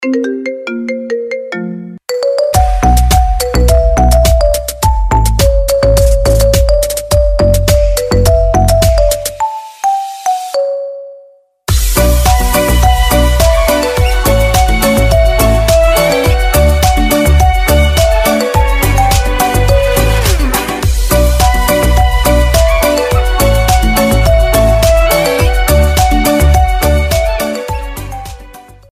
Marimba iPhone remix